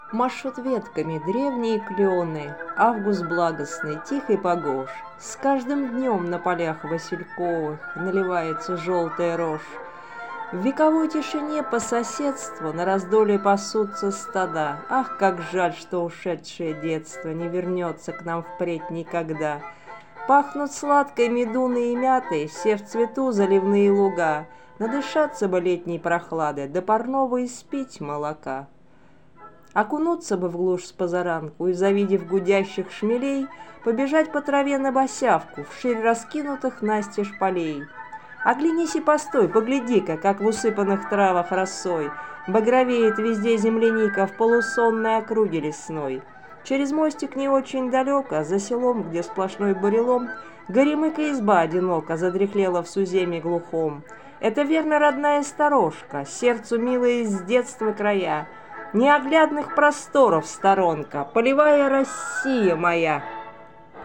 Музыка классики Озвучка автора